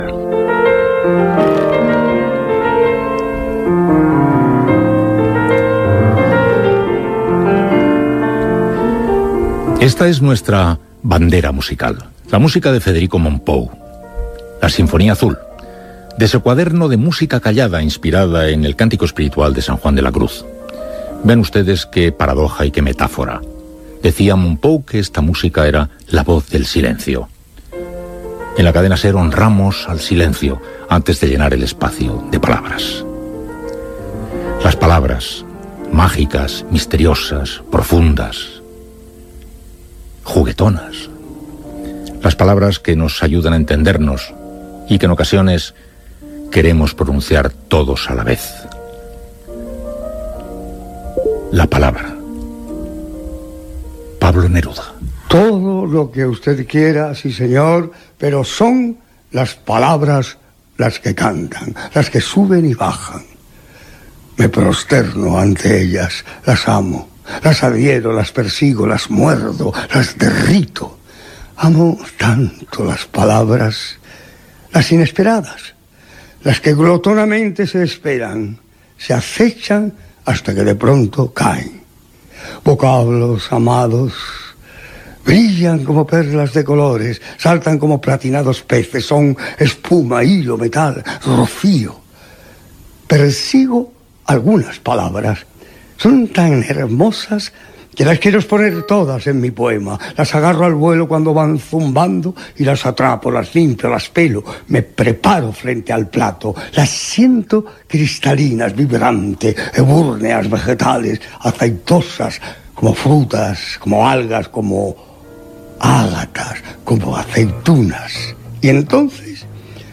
La sintonia de la Cadena SER basada en una composició de Federic Mompou. L'actor Juan Diego interpreta uns versos del poeta Pablo Neruda.